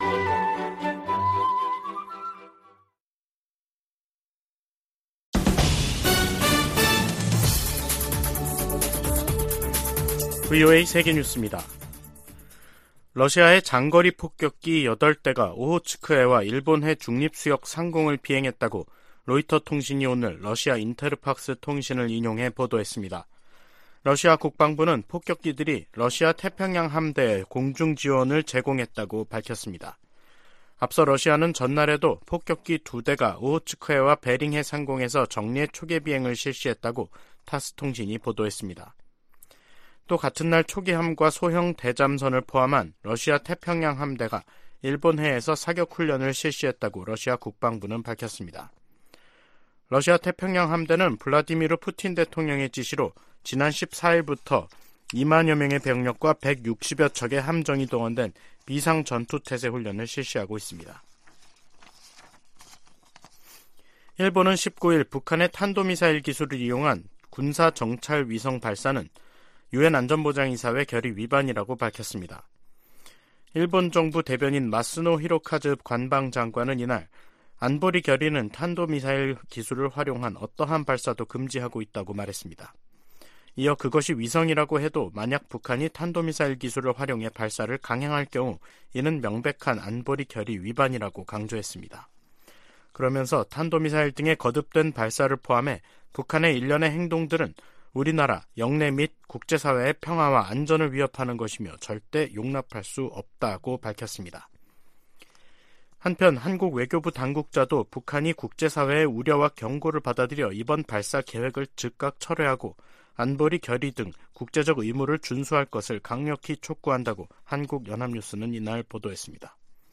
VOA 한국어 간판 뉴스 프로그램 '뉴스 투데이', 2023년 4월 19일 2부 방송입니다. 주한미군사령관은 북한 미사일이 워싱턴에 도달할 역량을 갖고 있으며, 7차 핵실험은 시간 문제라고 평가했습니다. 김정은 국무위원장이 첫 군사정찰위성 발사준비를 지시해 머지않아 위성발사 명분 도발에 나설 것으로 보입니다. 토니 블링컨 미 국무장관이 북한의 탄도미사일 발사와 핵 개발을 국제사회 공동 대응 과제로 꼽았습니다.